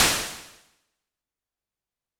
Drums_K4(42).wav